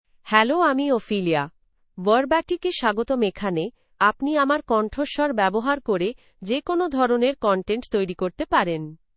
FemaleBengali (India)
Ophelia — Female Bengali AI voice
Voice sample
Listen to Ophelia's female Bengali voice.
Ophelia delivers clear pronunciation with authentic India Bengali intonation, making your content sound professionally produced.